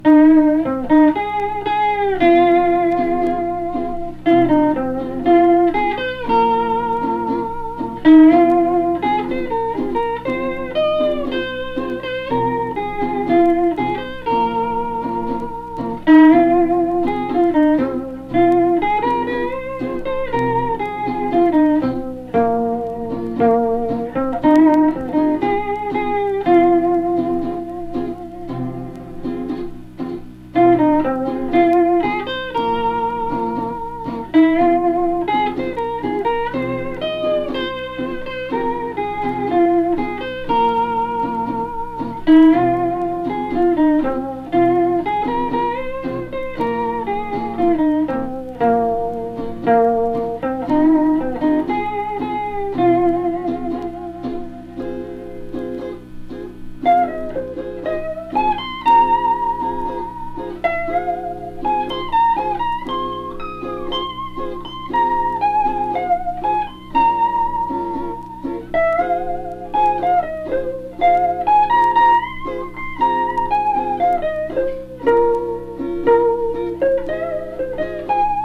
奄美大島の新民謡コンピレーションアルバム。聴きやすいアレンジになっており、入門編にピッタリ。ハワイアン奄美？